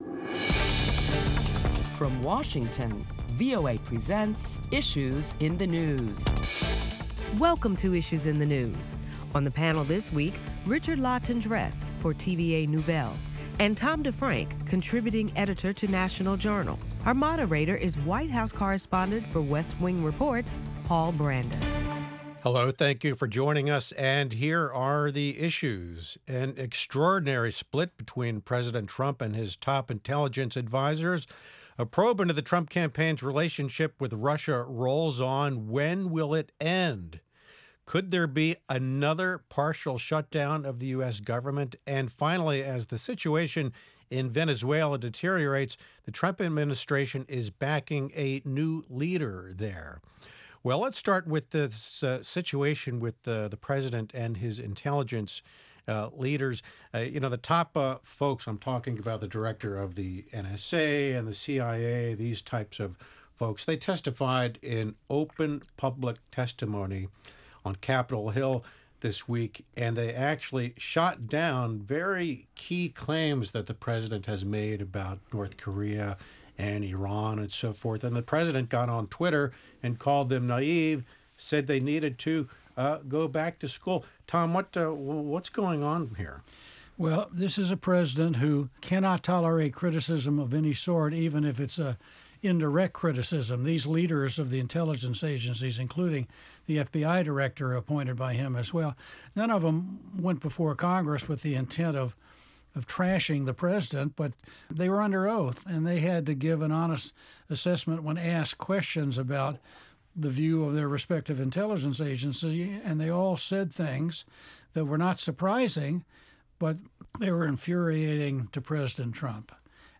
Listen to a round-table discussion among top Washington correspondents as they discuss the week's top stories including continuing talks over border wall funding.